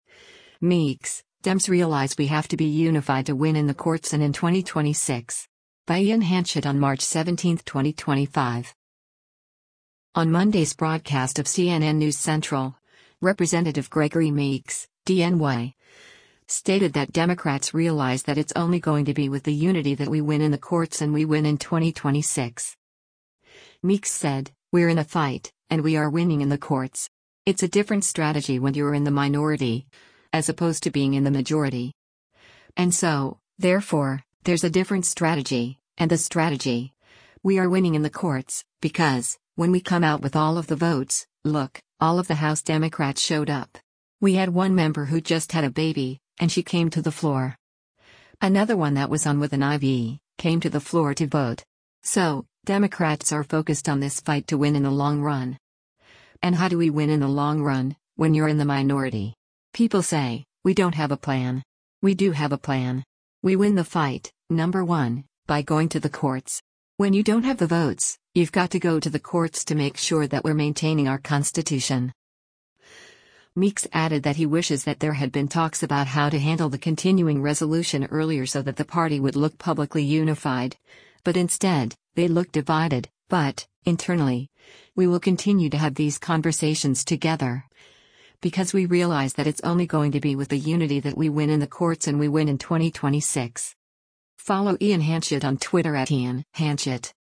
On Monday’s broadcast of “CNN News Central,” Rep. Gregory Meeks (D-NY) stated that Democrats “realize that it’s only going to be with the unity that we win in the courts and we win in 2026.”